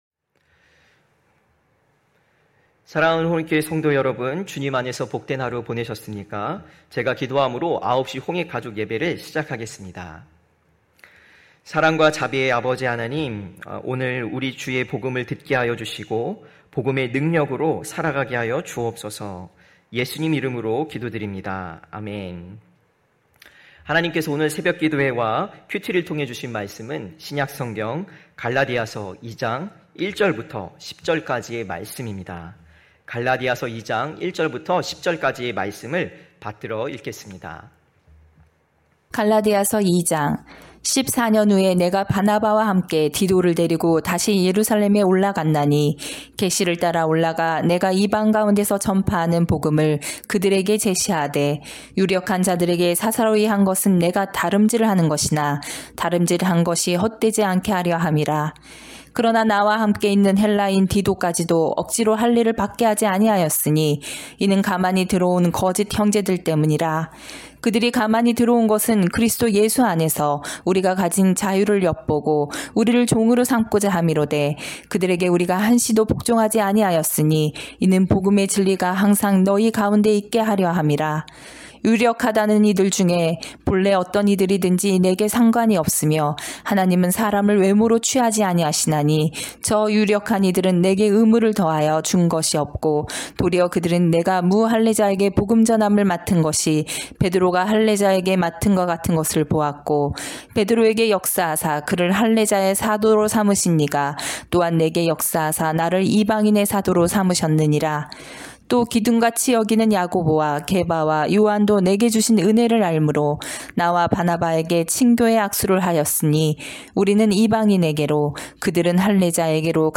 9시홍익가족예배(9월3일).mp3